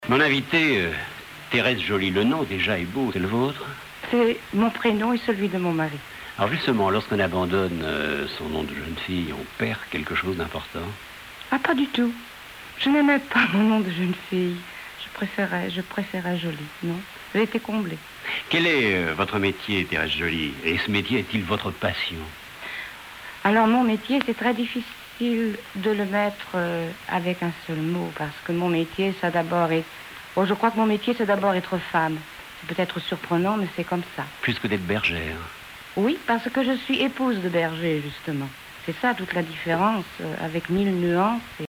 Témoignages à Jacques Chancel, émission Radioscopie
Catégorie Témoignage